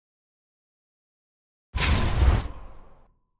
sfx-tier-wings-promotion-from-diamond.ogg